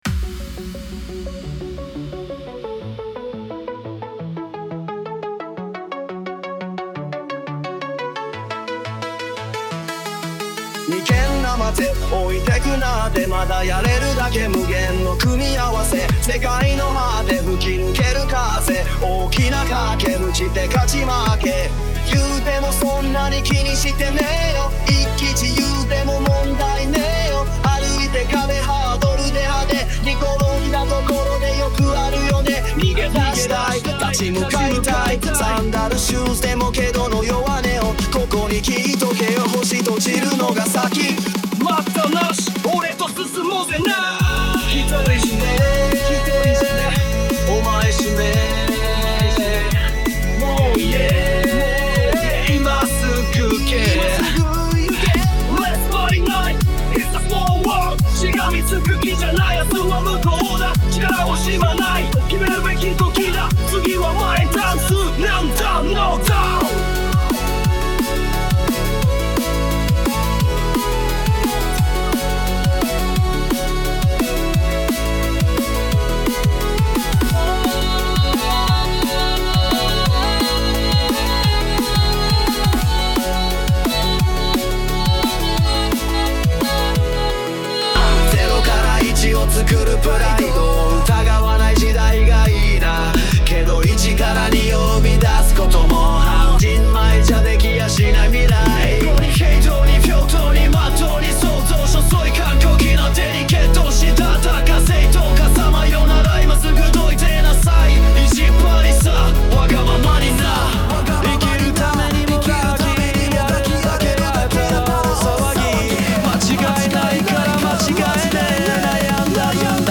暇だわ😅 17:00 4 18:00 2 20:00 さらに続編を翌日に… ⑥Next Stage BPM 174 ジャンルは レゲエ色を強めたRap × EDM 30回位出力をして それを マッシュアップ的に組み合わせて…